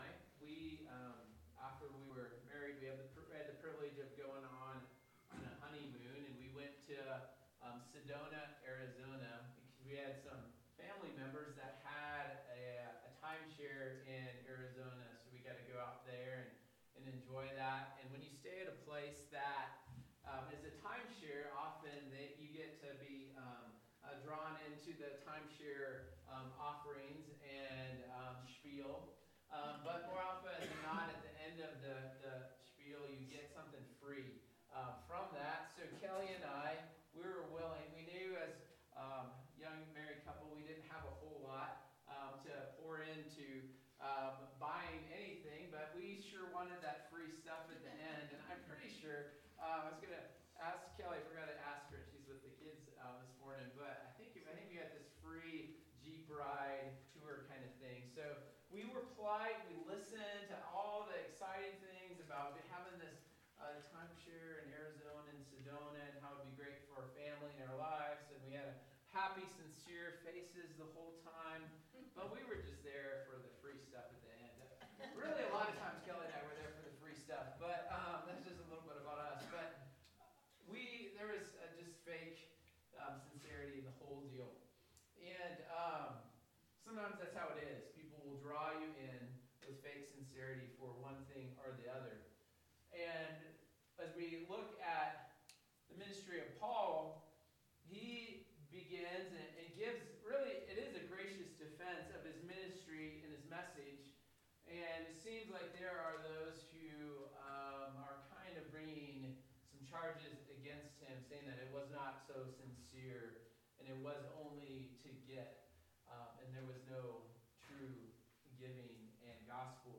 Sermons by Calvary Church Derby Hill